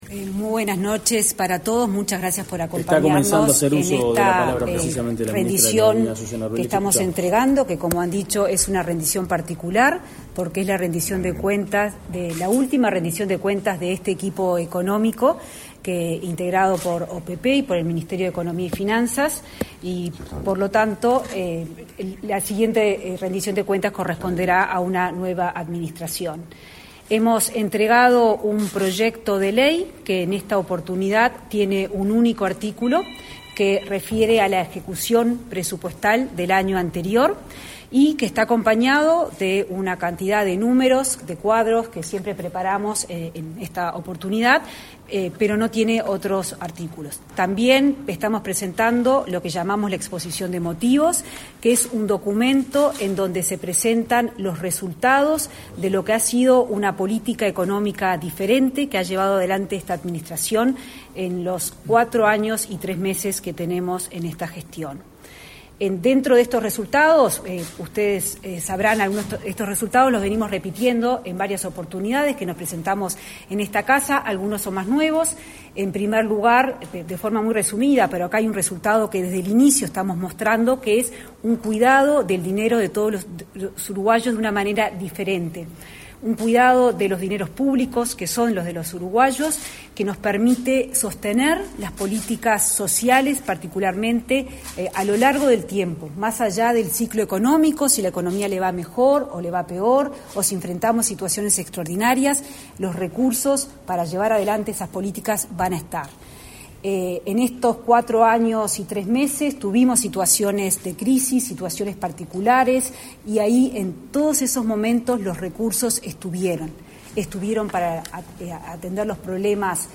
Declaraciones a la prensa de la ministra del MEF, Azucena Arbeleche
Declaraciones a la prensa de la ministra del MEF, Azucena Arbeleche 28/06/2024 Compartir Facebook X Copiar enlace WhatsApp LinkedIn Autoridades del Ministerio de Economía y Finanzas (MEF) entregaron, este 28 de junio, la Rendición de Cuentas al Parlamento. Luego, la ministra Azucena Arbeleche realizó declaraciones a la prensa.